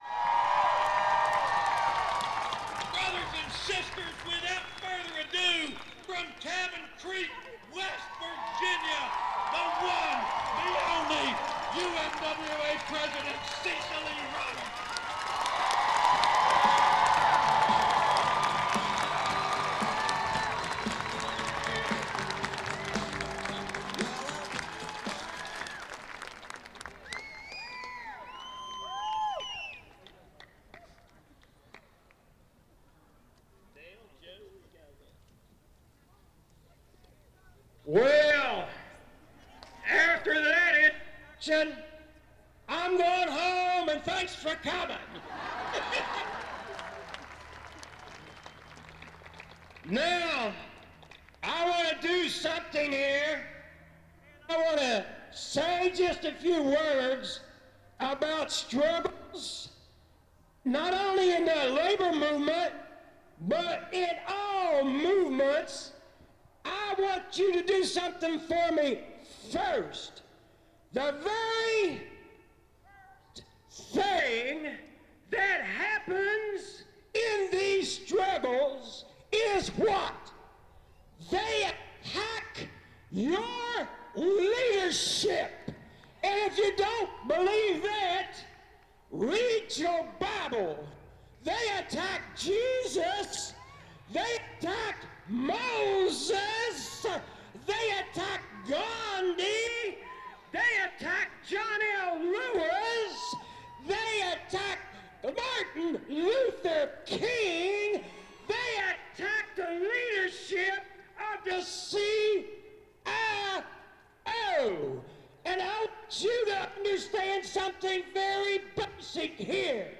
Recording of UMWA President Cecil Roberts' speech at the West Virginia Teachers' Strike rally at the state capitol